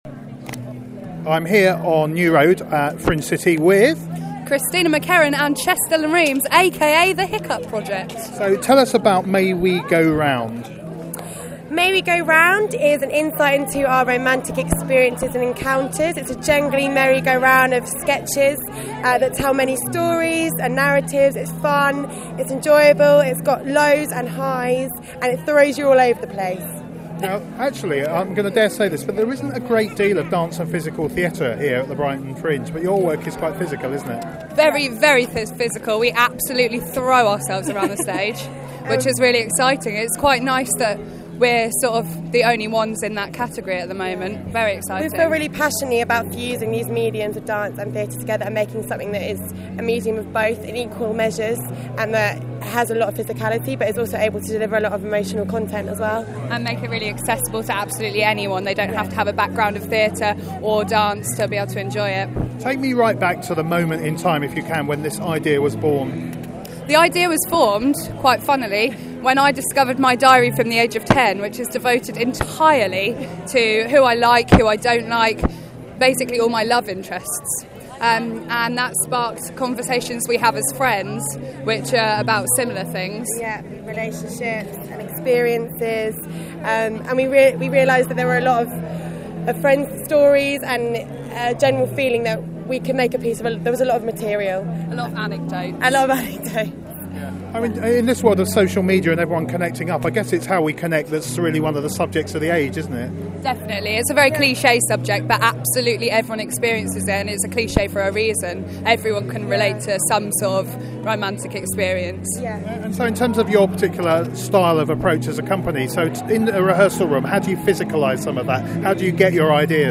Listento our interview with the Hiccup Project about May We Go Round